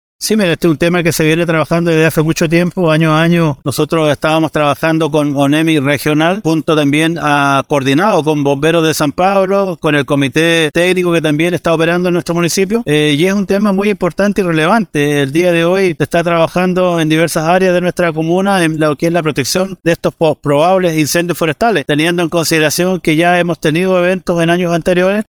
Con motivo de las alzas en las temperaturas, anunciadas por la Dirección Nacional de Meteorología para la Región de Los Lagos,  se instaló un comité operativo de emergencia para la comuna de San Pablo. El alcalde de dicha comuna, Juan Carlos Soto, detalló que este comité está conformado por Bomberos de San Pablo, Conaf, y departamentos sociales y operativos de la municipalidad y tendrán la responsabilidad de enfrentar este tipo de emergencias para asegurar la protección de los ciudadanos.